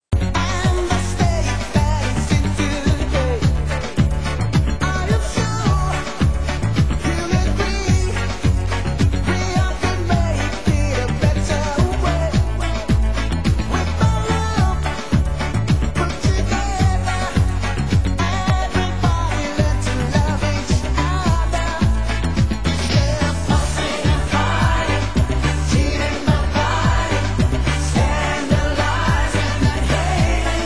Genre: UK House
12" Mix, Radio Mix